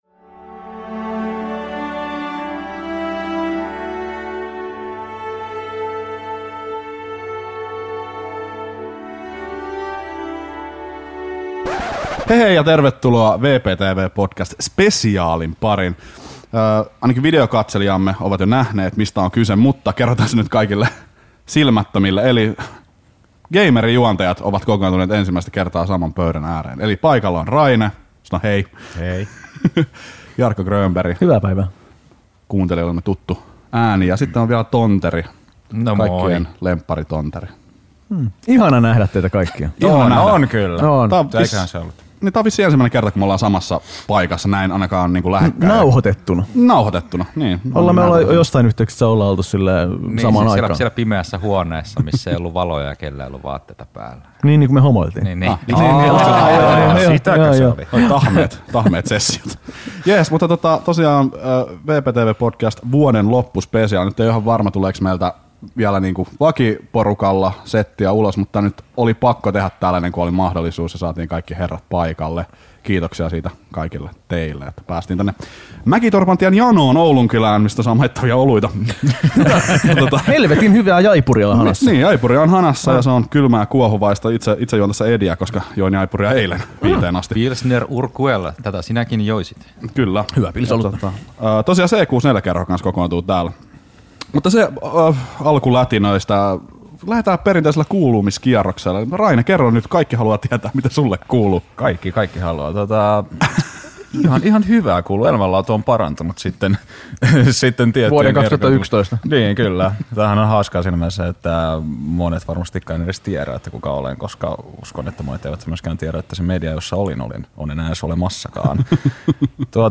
Nyt ohjelman juontajat kokoontuvat ensimmäistä kertaa saman pöydän ääreen kertomaan omia muistojaan ohjelman varrelta. Luvassa myös kaikkien kuulumiset näin ohjelman jälkeen.